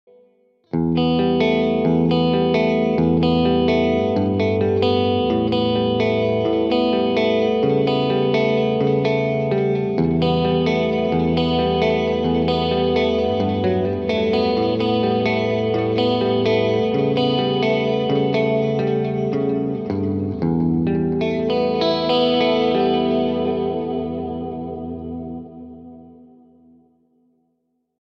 This Amp Clone rig pack is made from a Mesa Boogie JP2C, Mark IV, Mark V, Mark VII and a Mesa Triaxis preamp head.
RAW AUDIO CLIPS ONLY, NO POST-PROCESSING EFFECTS